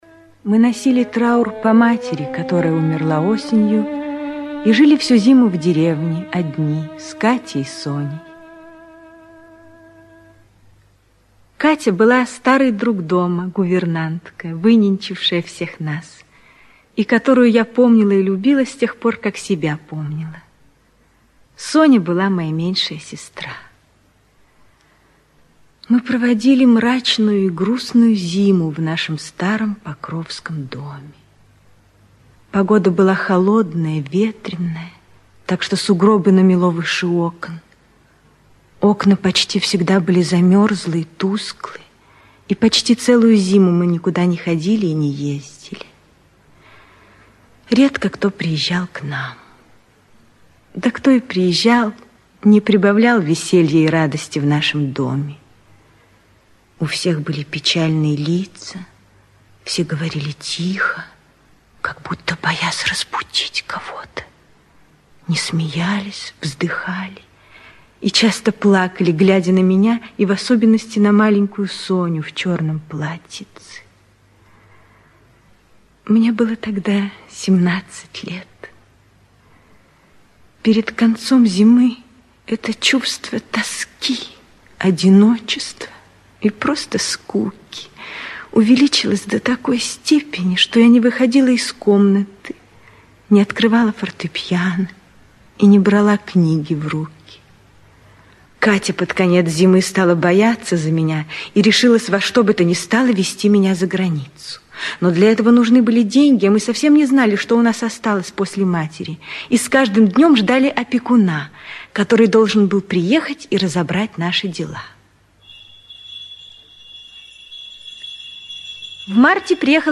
Аудиокнига Семейное счастье (спектакль) | Библиотека аудиокниг
Aудиокнига Семейное счастье (спектакль) Автор Лев Толстой Читает аудиокнигу Эмма Попова.